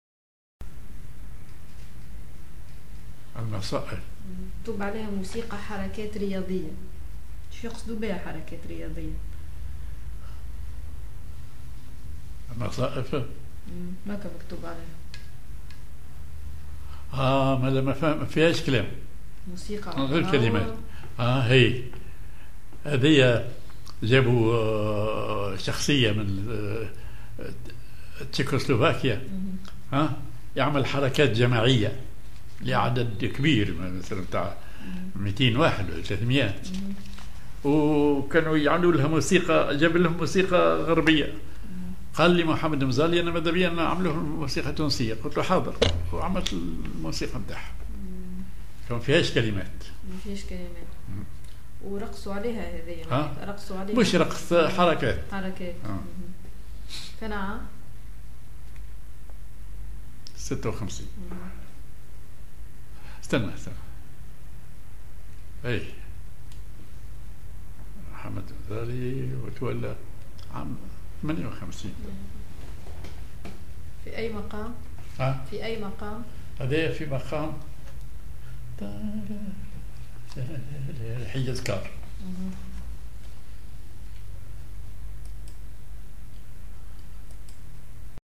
تسجيل الحوار مقطوع
نشيد